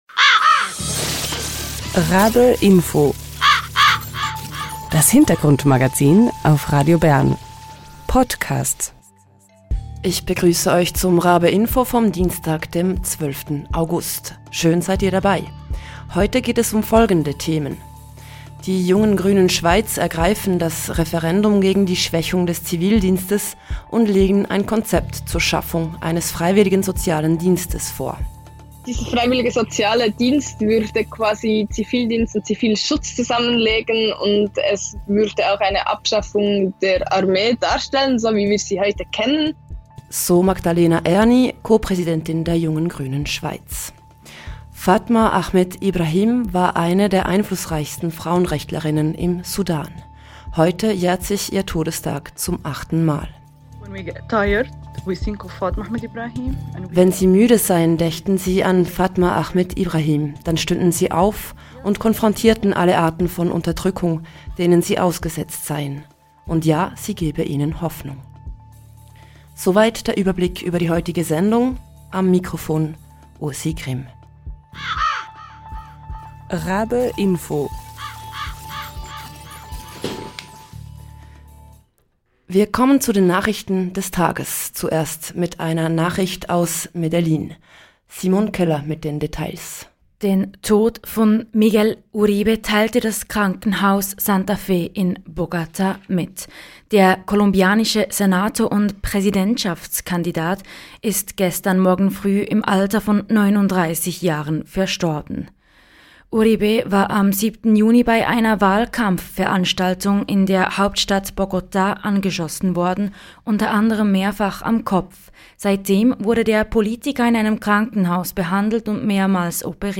Und: Fatima Ahmed Ibrahim war eine sudanesische Frauenrechtlerin und die erste Parlamentarierin. Zu ihrem achten Todestag hören wir ein Porträt.